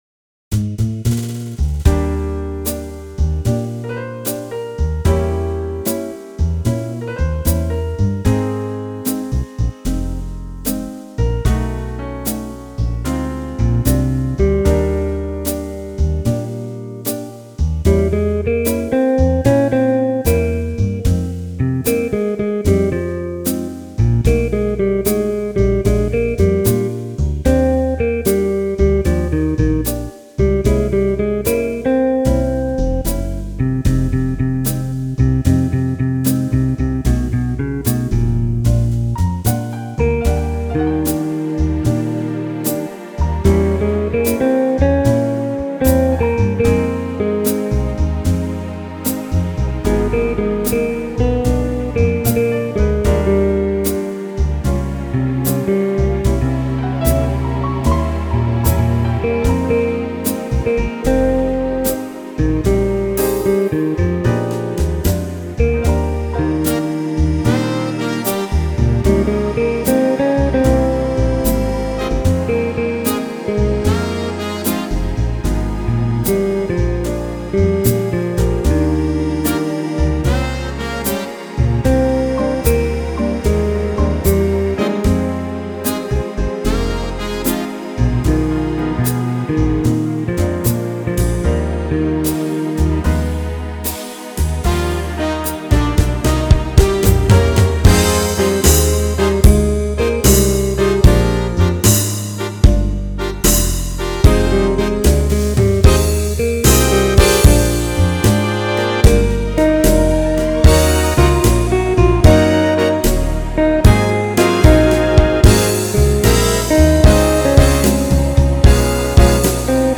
jazz band  Why WOULDN”T you perform this song?
a definite jazz feel – lush arrangement not overdone